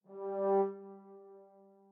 strings7_18.ogg